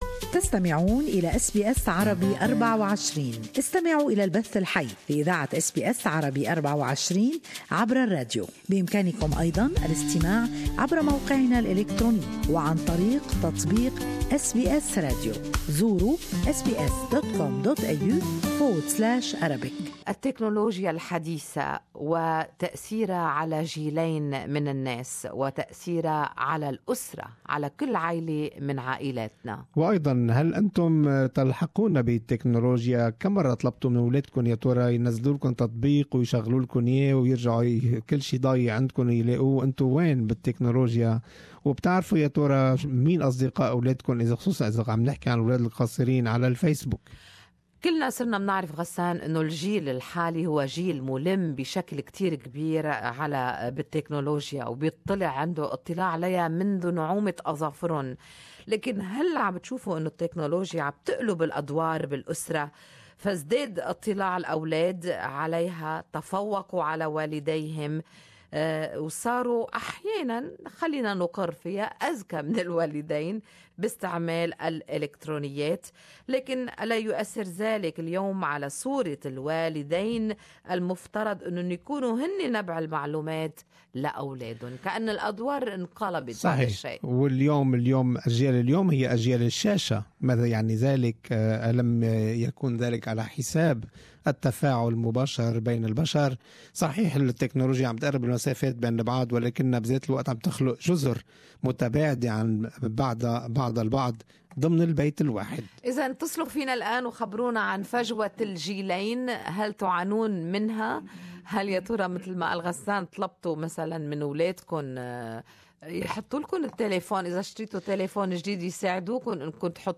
Good Morning Australia listeners share their insights on this topic.